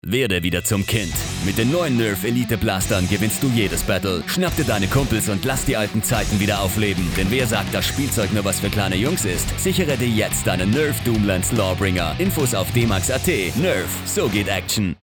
markant, sehr variabel, dunkel, sonor, souverän
Mittel minus (25-45)
Wienerisch
Commercial (Werbung)